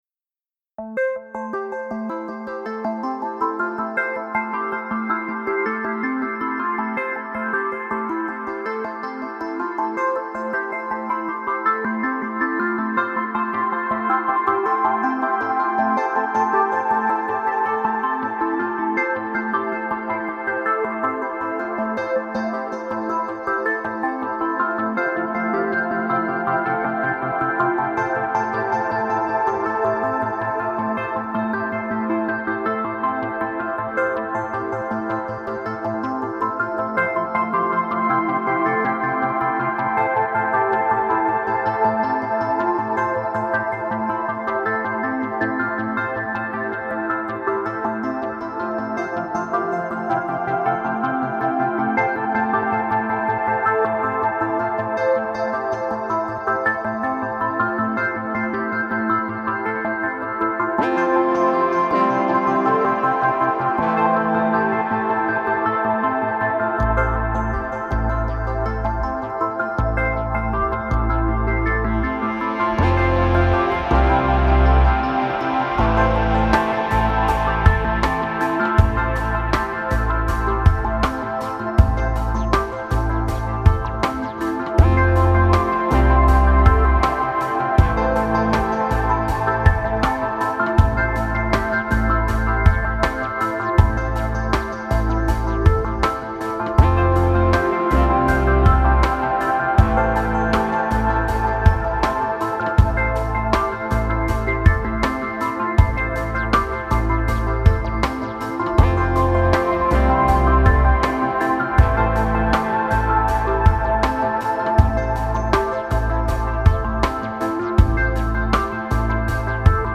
Genre: Electronic SpaceSynth.